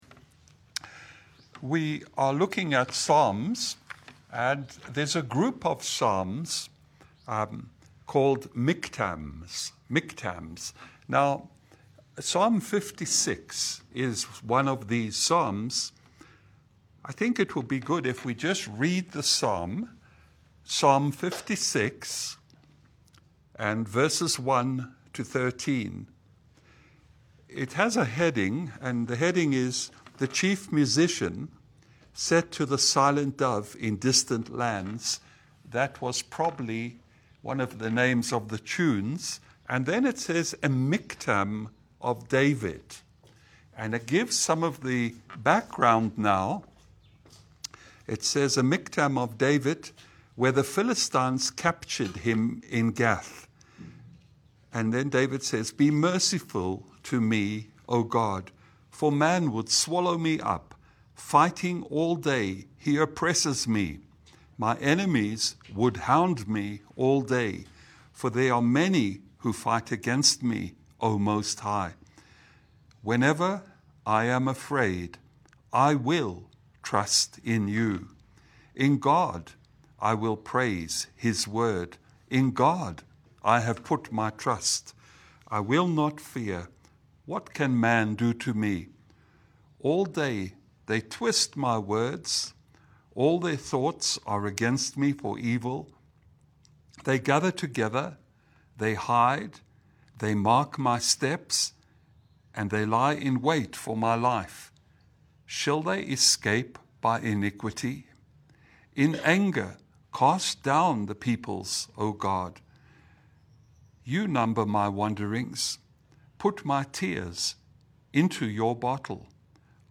Psalm 56 Sermon: How Faith Triumphs Over Fear
Psalm 56:1 Service Type: Lunch hour Bible Study « From Beggar to Worshiper